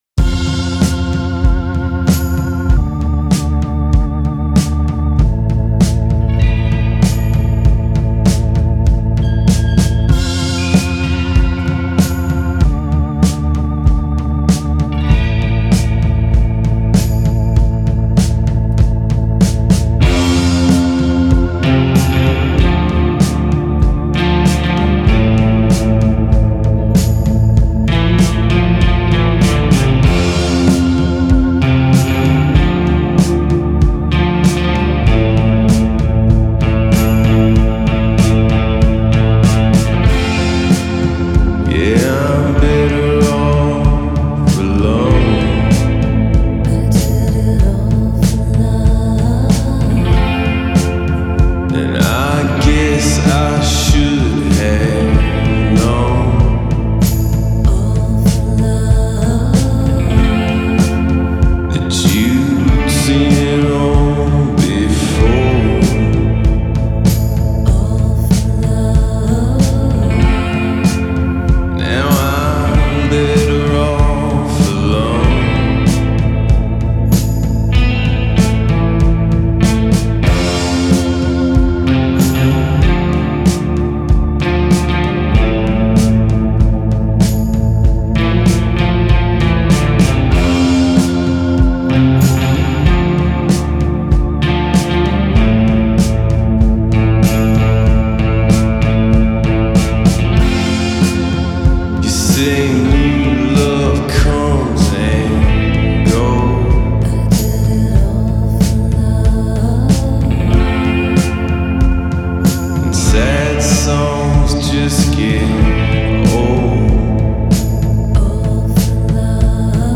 Genre: Indie Pop, Rock, Alternative